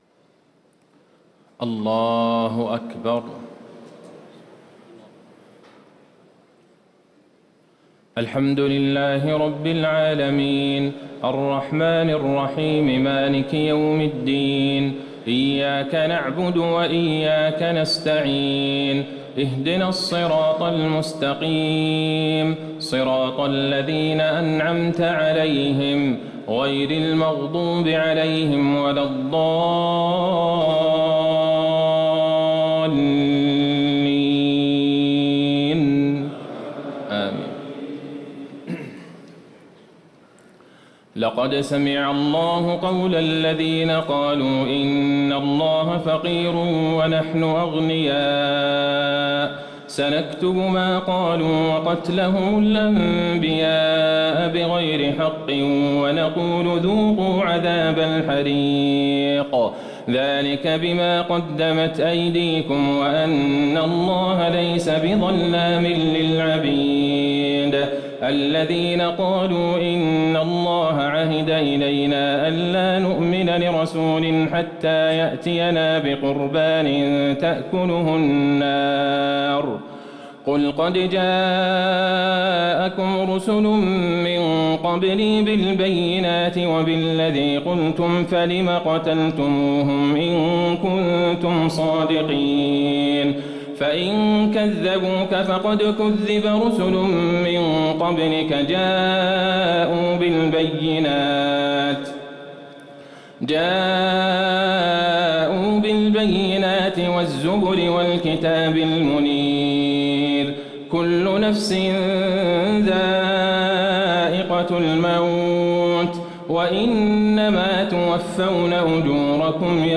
تهجد ليلة 24 رمضان 1438هـ من سورتي آل عمران (181-200) و النساء (1-24) Tahajjud 24 st night Ramadan 1438H from Surah Aal-i-Imraan and An-Nisaa > تراويح الحرم النبوي عام 1438 🕌 > التراويح - تلاوات الحرمين